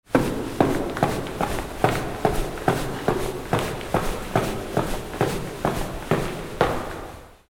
Fast Footsteps Of Person In Jacket Ascending Stairs Sound Effect
Fast-footsteps-of-person-in-jacket-ascending-stairs-sound-effect.mp3